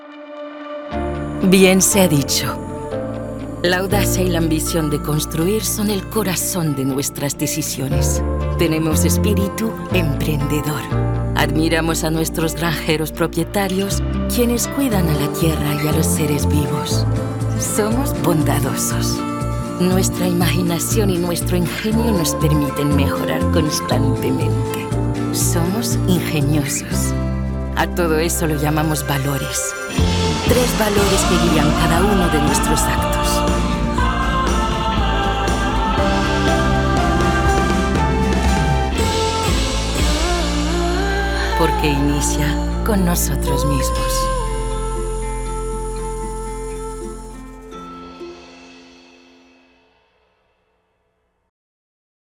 Voix annonceur (espagnol) – Nutri Oeufs
dynamique, sourire dans la voix, inspirante